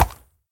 Minecraft.Client / Windows64Media / Sound / Minecraft / mob / horse / soft4.ogg